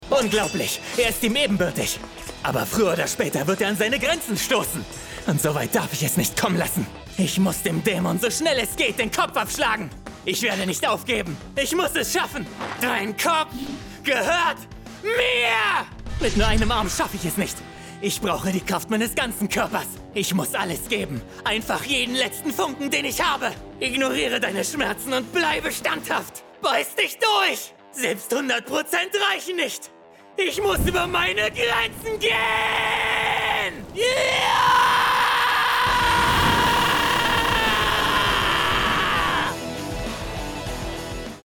Male
Approachable, Bright, Bubbly, Character
I record from my professionally treated home studio, delivering broadcast-ready audio.
Explainer - Elearning (Corporate).mp3
Microphone: Neumann TLM 103